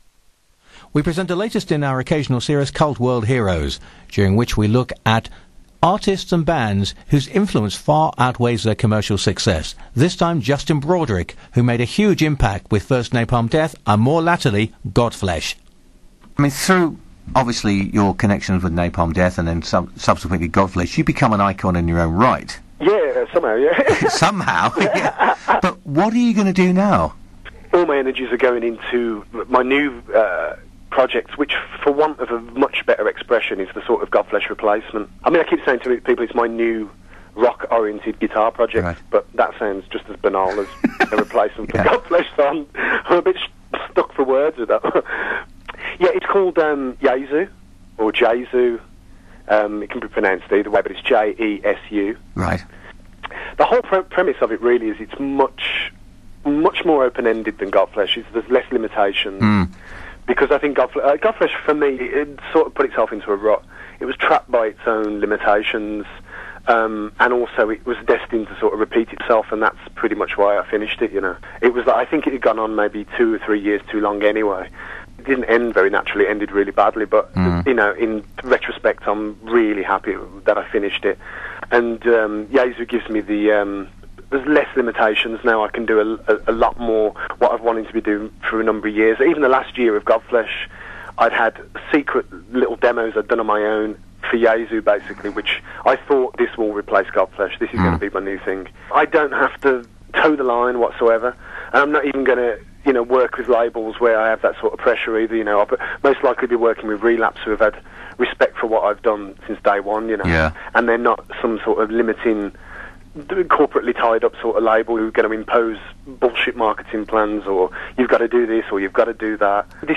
[13th May] - Justin Interview
JKFleshInterview.mp3